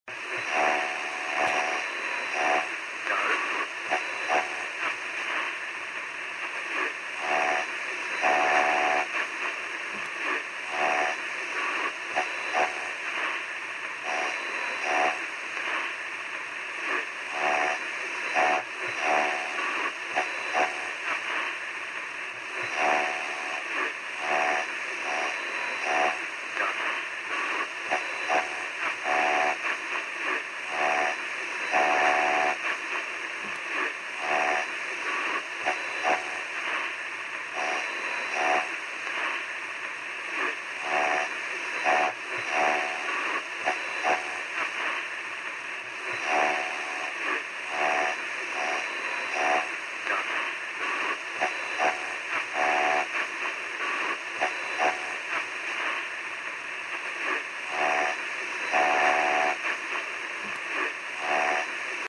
8. Шум помех в радиоэфире